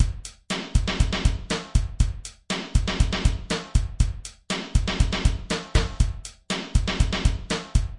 Drum Percussion Loops " Drum Loop Rock02 120
Tag: 回路 命中 岩石 节奏 冲击 120-BPM 打击乐器 击败 打击乐器环 量化 鼓环 常规